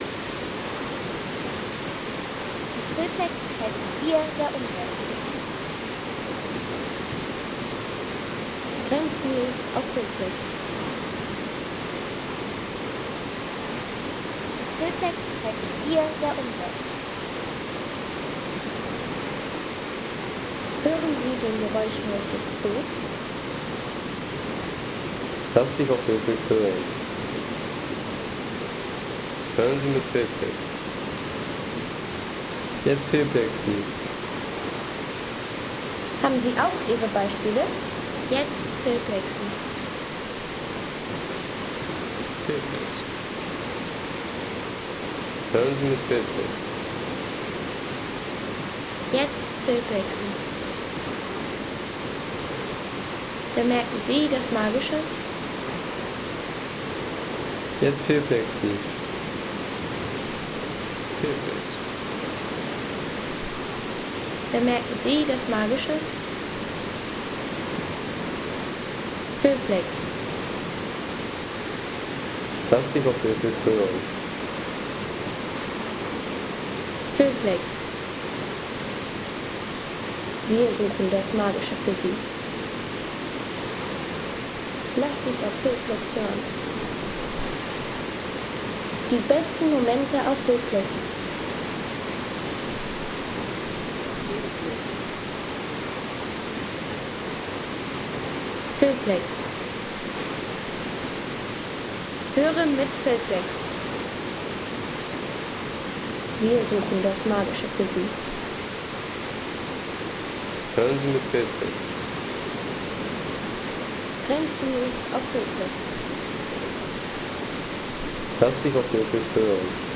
Landschaft - Wasserfälle
Hautnah am Stuibenfall – Ein Naturerlebnis der besonderen Art.